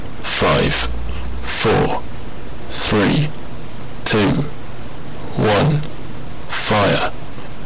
Dialogue » three
描述：Dialogue "Three"
标签： three digit countdown
声道立体声